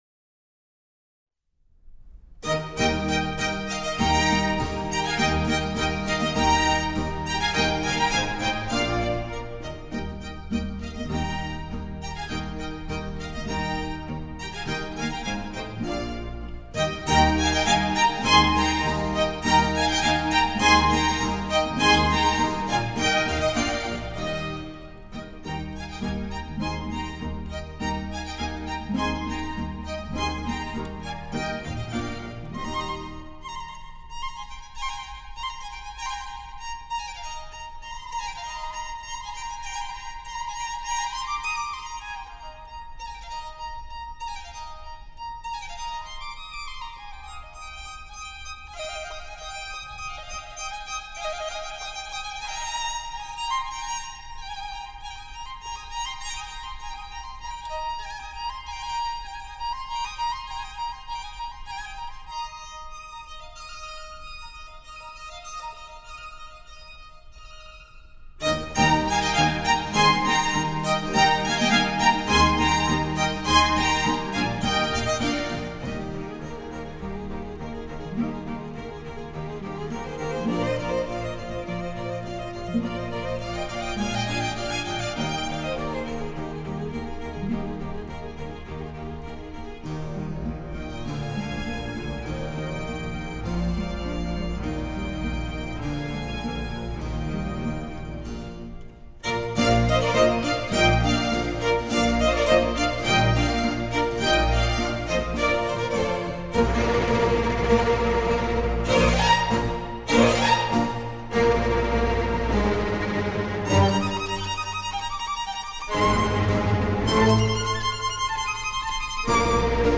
音樂類型：古典音樂
第一樂章是以快板表現迎接新春的喜悅，獨奏小提琴模仿「鳥歌」，總奏模仿「潺潺的水聲」。
第二樂章中運用最緩板，由獨奏小提琴描寫出在草原上打盹的牧羊人。